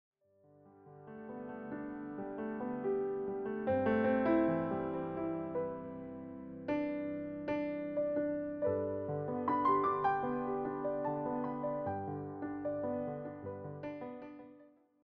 presented as relaxed piano interpretations.